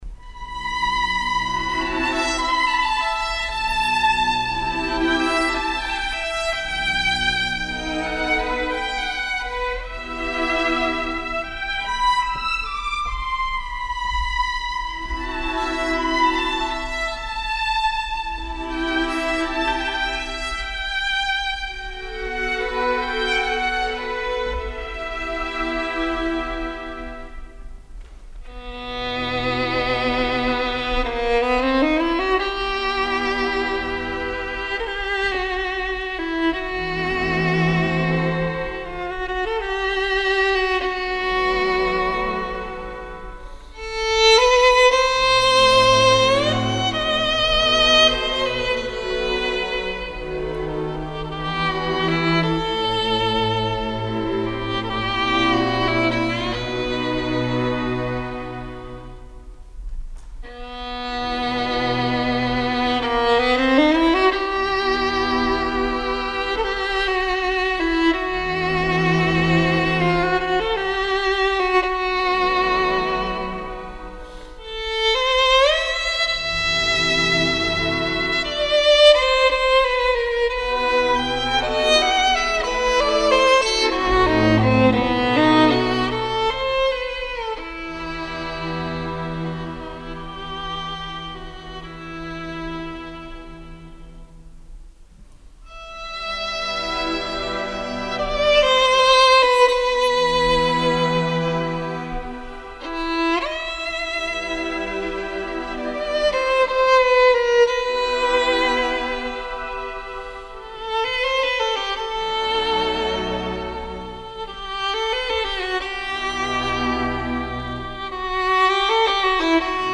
Întreaga sa creație muzicală se încadrează în sfera curentului romantic.
Ciprian-Porumbescu-Balada-solist-Ion-Voicu.mp3